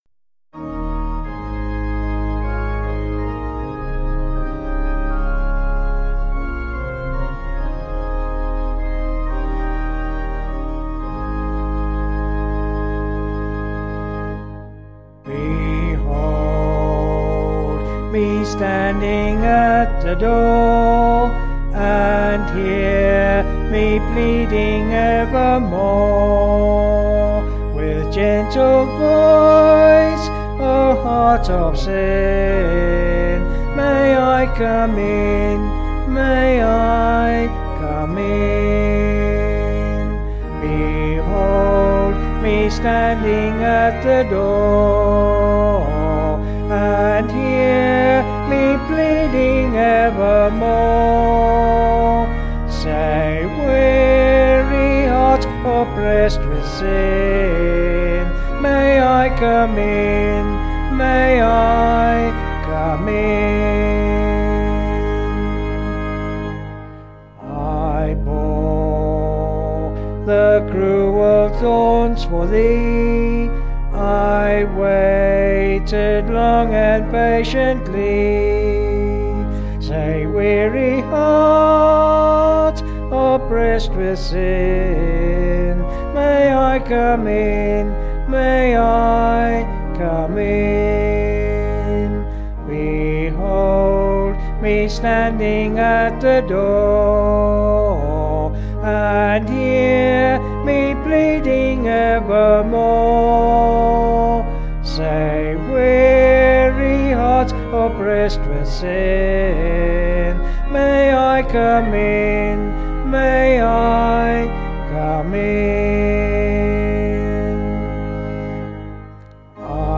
Vocals and Organ   264.8kb Sung Lyrics